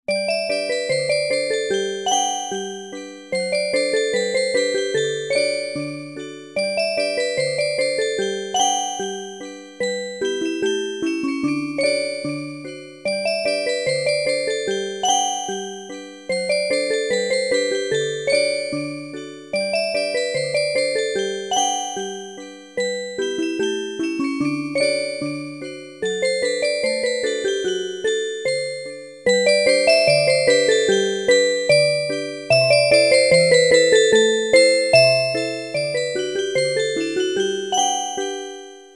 オルゴールによるアレンジ曲。
愛らしいメロディーがオルゴールの音色で一層楽しさが増しています。